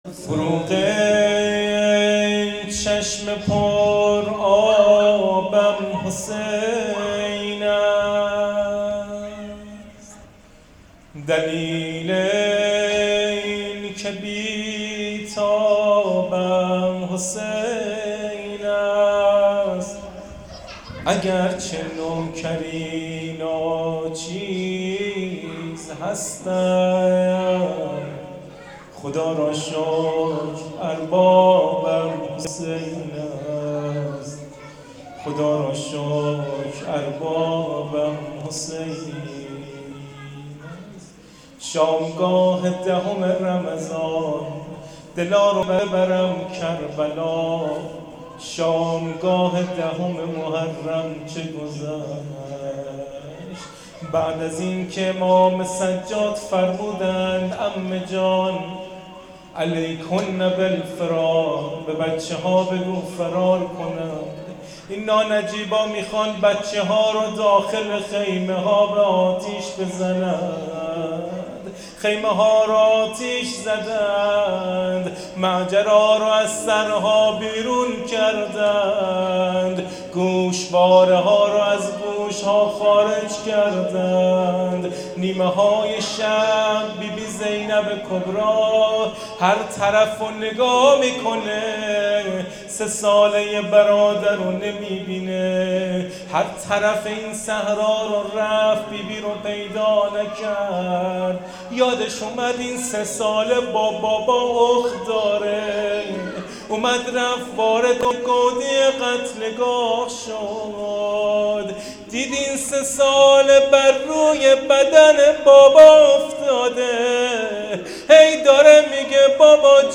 روضه شب یازدهم محرم